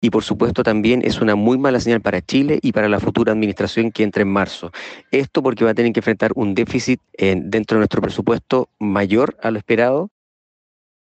En la misma línea, el diputado Stephan Schubert advirtió que estas cifras constituyen una muy mala señal para el país y anticipan un escenario complejo para la futura administración de Kast.